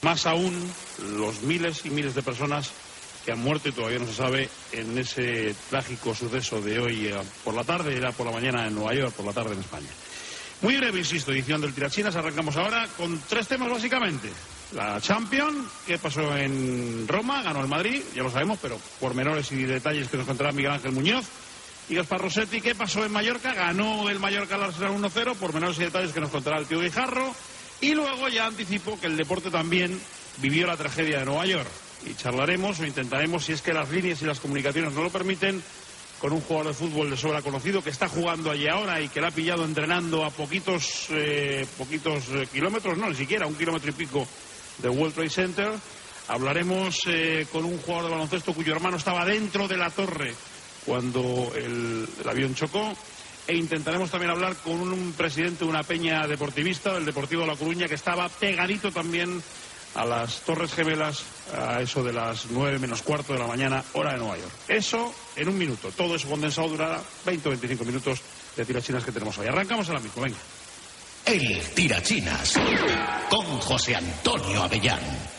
Esportiu
FM